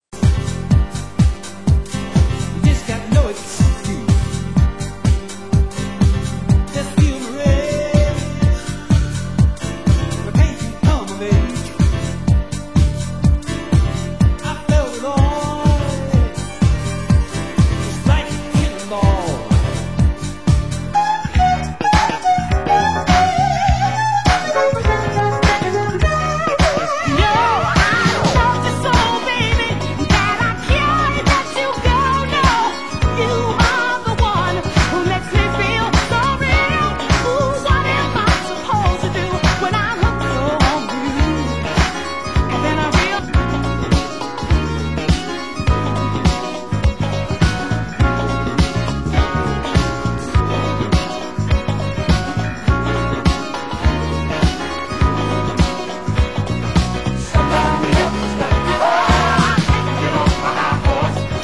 Genre: US House
vocal & instr.